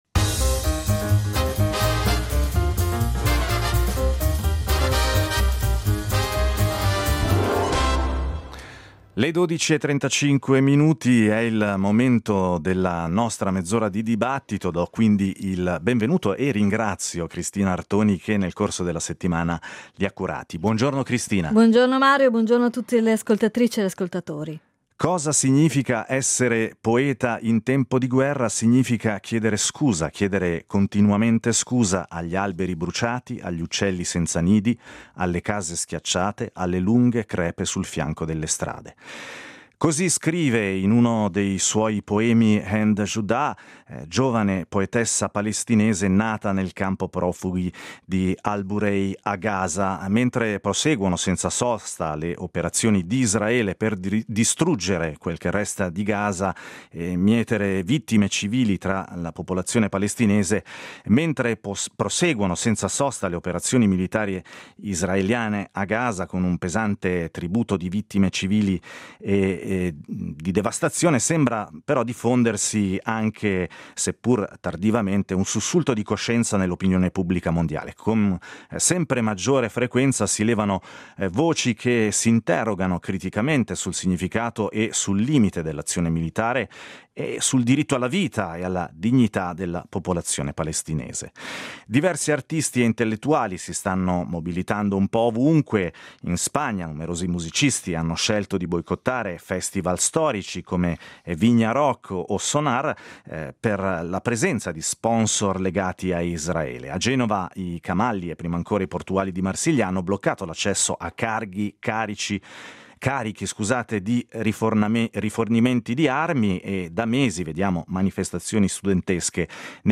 Ad Alphaville abbiamo avuto ospiti Francesca Albanese , giurista e docente italiana, specializzata in diritto internazionale e diritti umani.